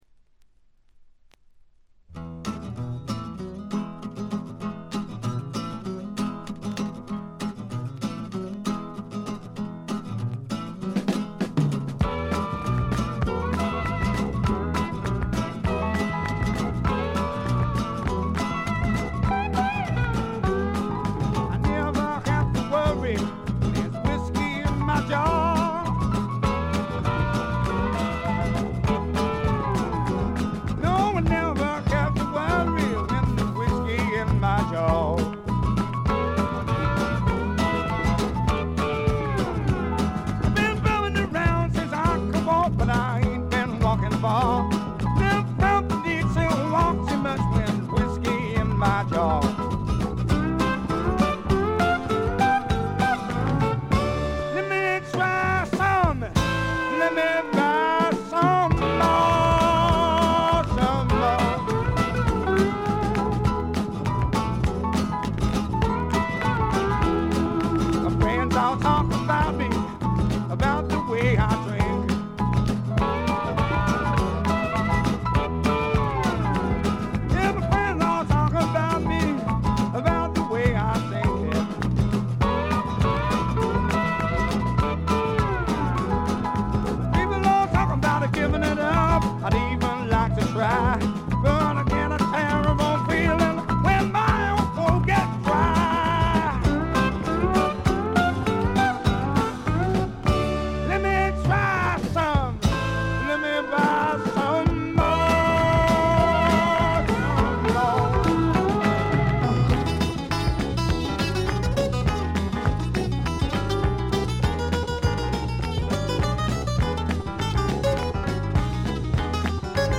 他はチリプチや散発的なプツ音は出るもののまずまず。
へヴィーな英国流ブルースロックをやらせたら天下一品。
試聴曲は現品からの取り込み音源です。